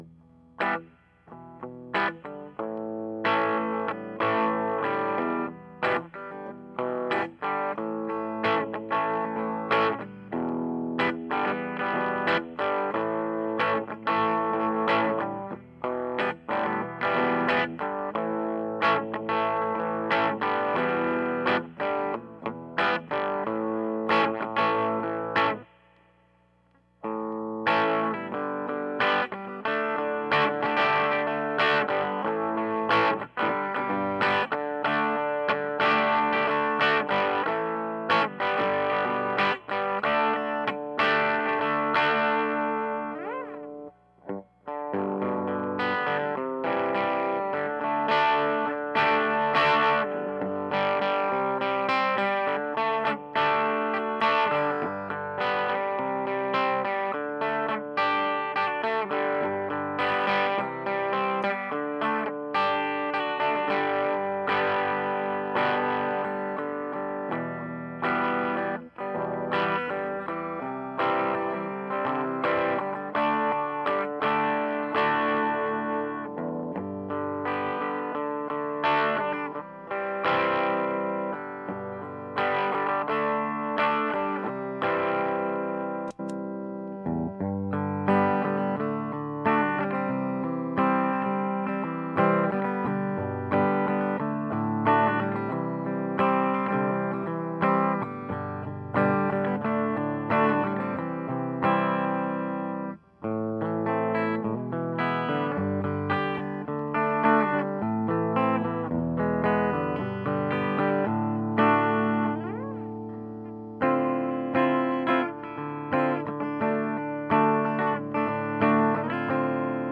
You quest prompted me to throw it in front of an amp and give it a try at an electric through a tube amp since I couldn't find any Youtube vids used on an amp.
Reverb off and didn't pay much attention to EQ and was played as set. A little bit of the 'dirt' channel and some clean. - AT2005USB was centered about 3" off grill of the amp - Recorded via USB into Audacity. Did not normalize or anything else.